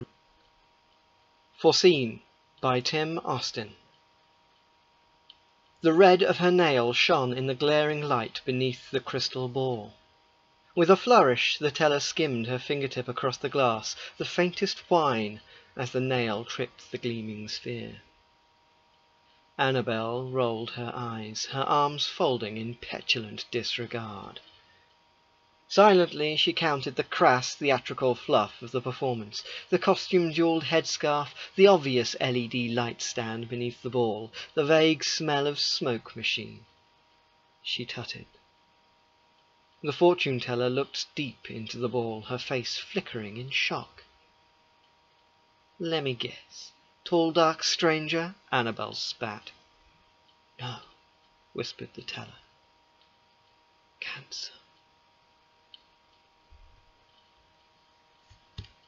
Forseen - An Audio Reading